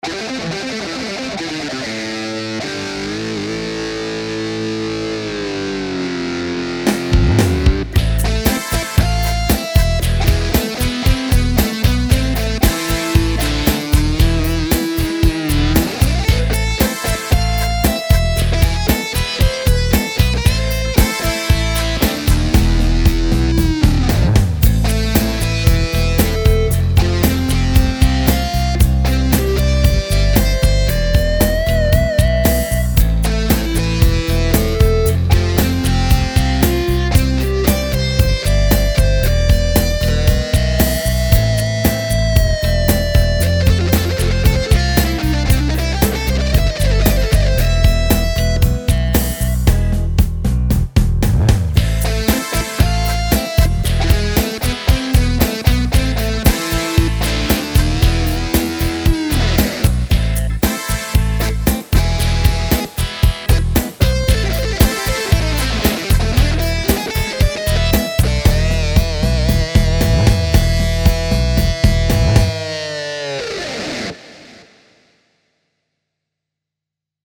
Вот пример - делаю стиль, на середине так сказать пути. вступление, куплет припев и вбивка одна пока что. Часть сам делал арпеджио, часть с монтажа. И сорри за косячную игру, просто по-быстрому сейчас наиграл.